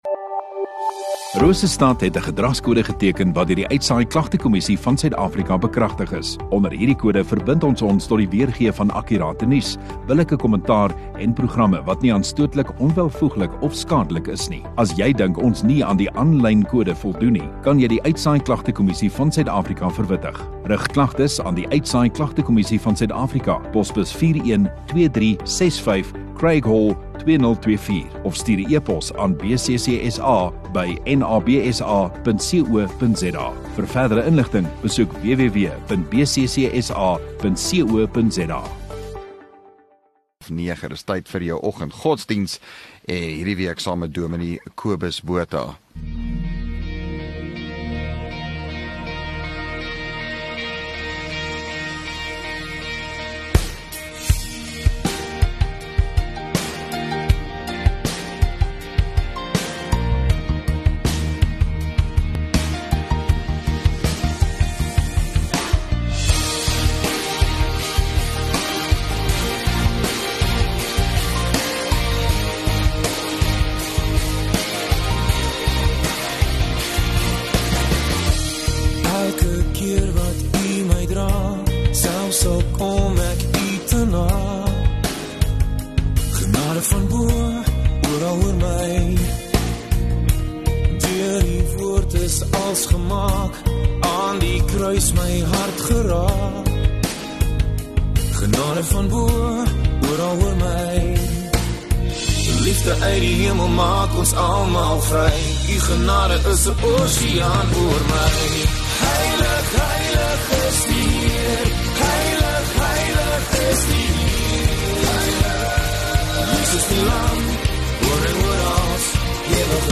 15 May Donderdag Oggenddiens